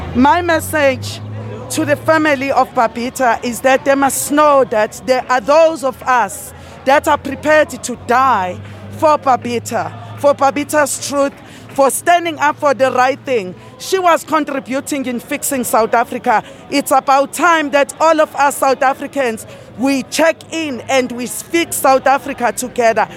Makhosi Khoza who is ActionSA’s candidate for Mayor of eThekwini in this year’s local government elections says she stands with Deokaran and wants to see justice prevail.
11-am-LIVE-MAKHOSI-KHOZA.wav